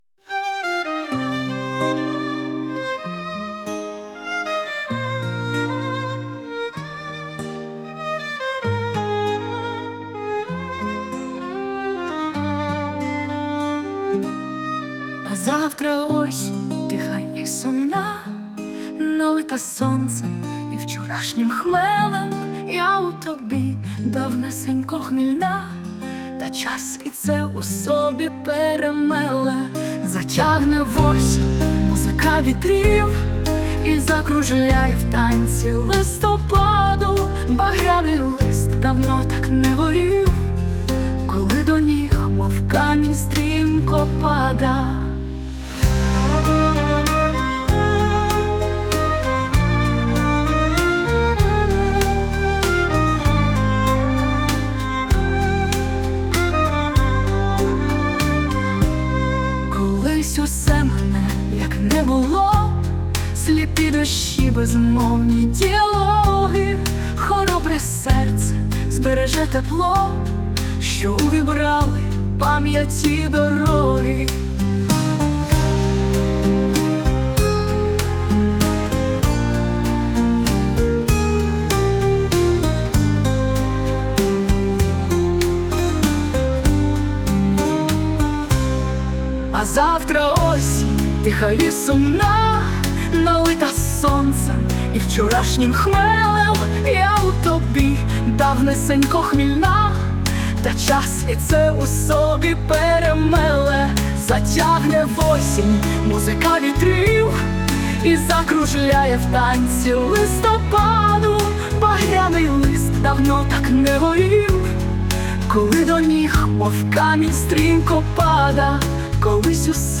А завтра - осінь_ вальс.mp3
Пісня створена в нейромережі
СТИЛЬОВІ ЖАНРИ: Ліричний
приспів фантастичний ...
настрій чудовий